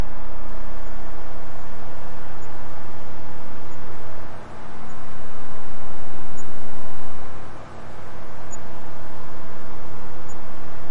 描述：记录自arp2600va free vsti，随机参数算法在flstudio 3.0中
Tag: 合成器 发电机 振荡器